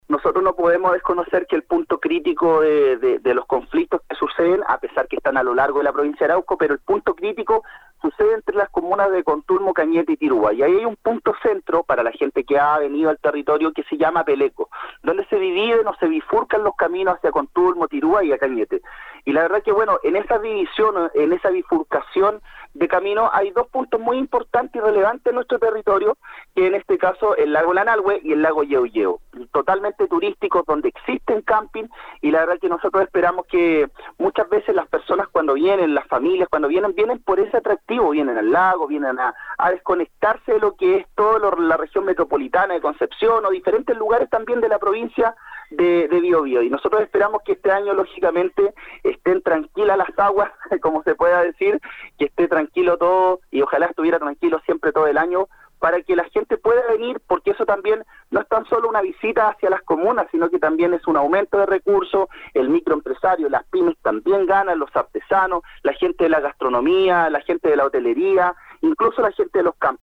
El Consejero Regional por la Provincia de Arauco, Christopher Gengnagel Carrasco, en entrevista con Nuestra Pauta, se refirió a la visión que tienen, en terreno, sobre la situación que se vive en la provincia.